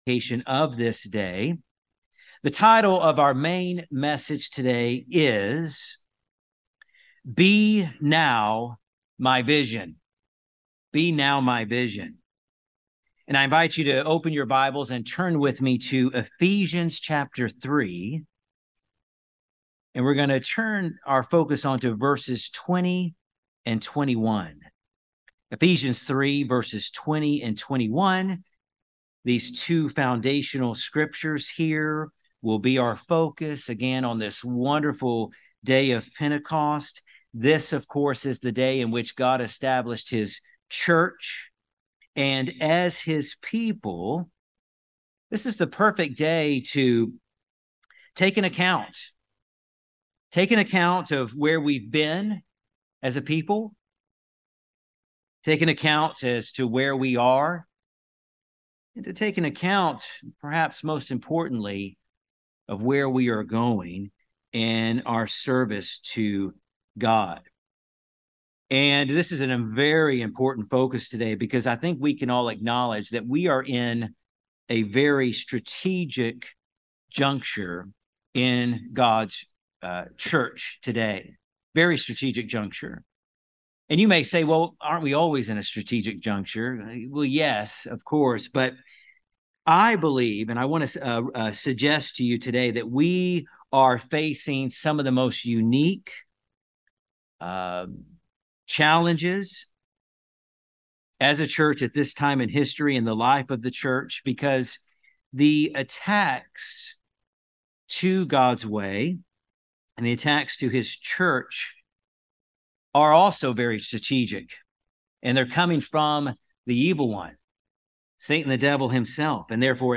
This sermon outlines the three main areas where our vision as Christians should be focused.